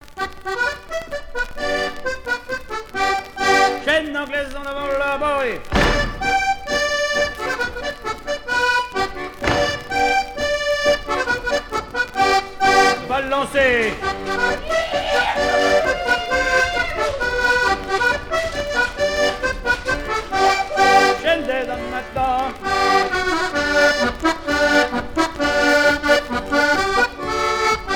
danse : quadrille : chaîne anglaise
Pièce musicale éditée